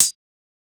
UHH_ElectroHatB_Hit-14.wav